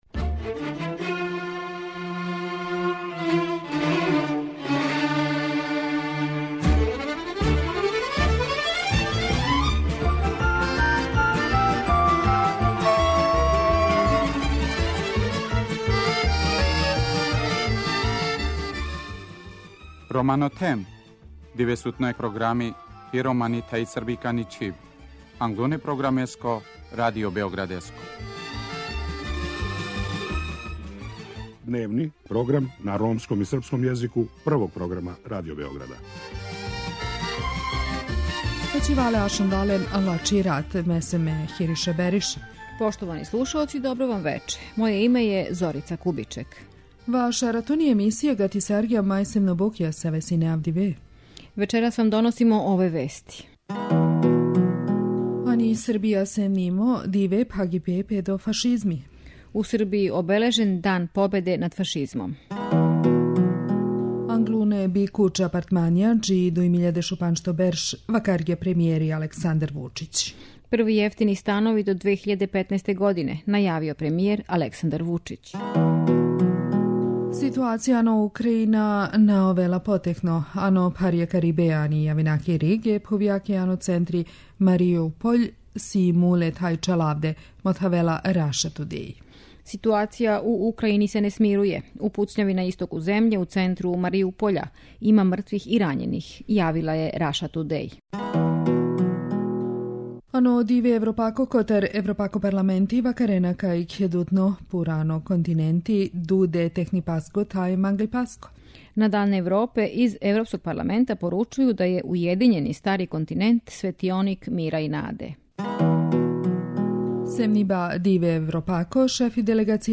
преузми : 16.73 MB Romano Them Autor: Ромска редакција Емисија свакодневно доноси најважније вести из земље и света на ромском и српском језику. Бави се темама из живота Рома, приказујући напоре и мере које се предузимају за еманципацију и интеграцију ове, највеће европске мањинске заједнице.